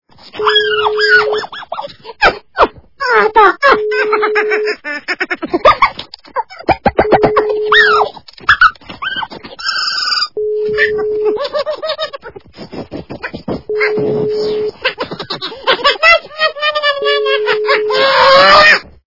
Смешные